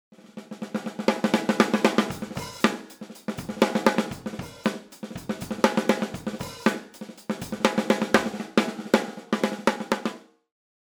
DWの定番スネアと言えばコレクターズメイプルシリーズ。10+6プライのメイプルシェルを採用し、明るく抜けの良いサウンドで粒立ちの良さとレンジの広さは、正にDWの代表的なサウンドです。